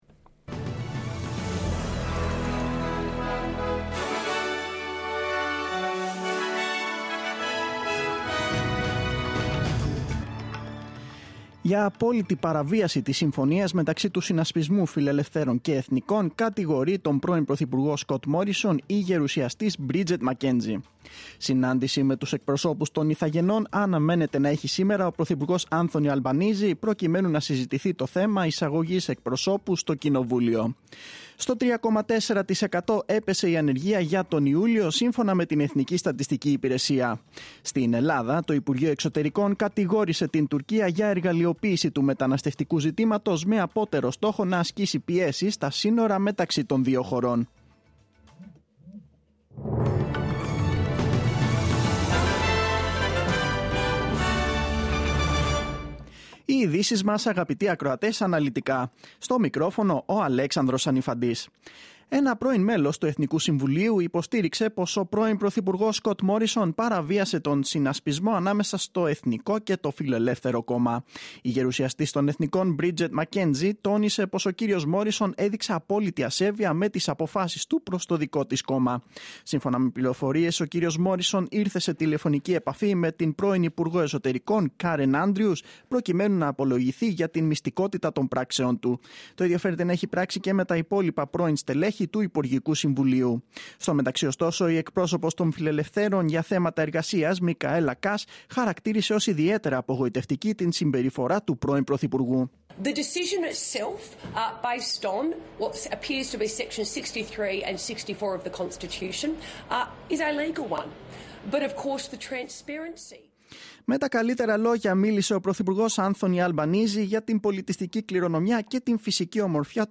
Δελτίο Ειδήσεων: Πέμπτη 18.8.2022